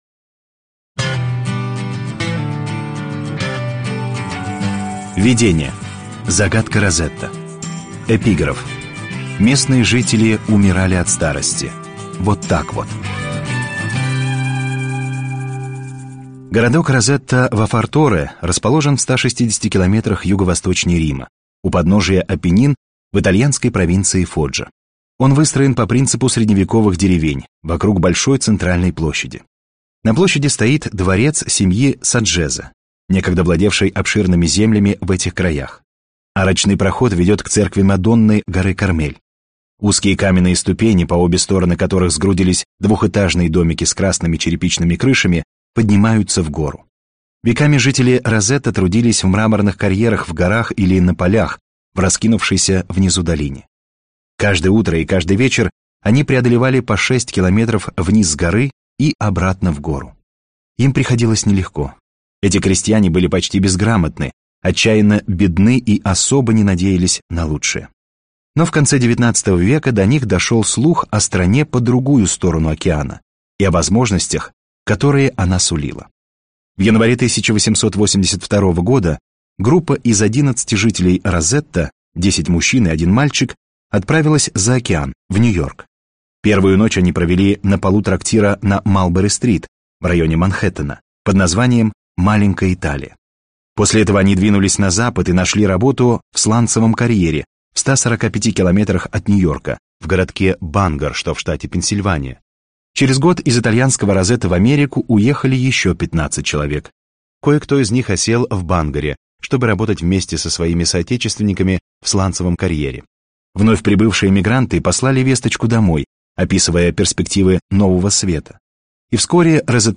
Aудиокнига Гении и аутсайдеры. Почему одним все, а другим ничего?